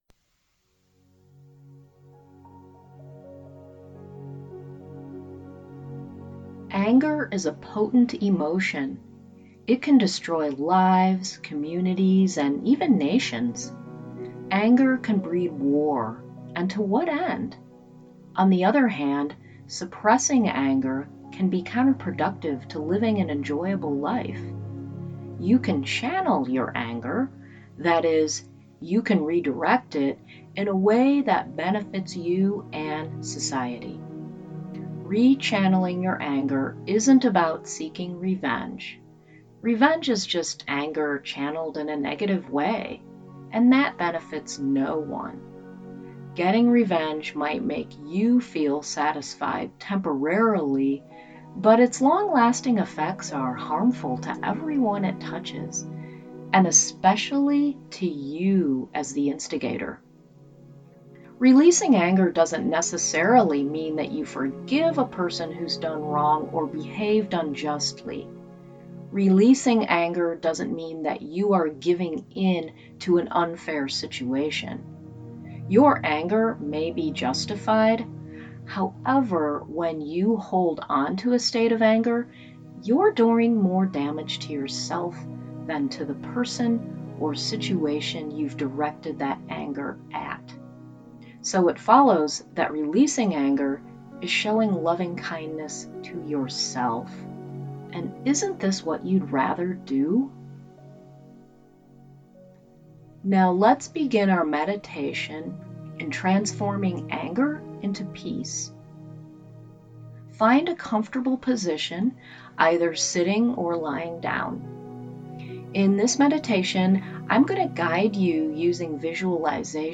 Use this meditation to transform anger you might be feeling toward a person or a situation and invite peace to fill you instead. Through the powerful tool that is the mind, you can control what you want to experience emotionally.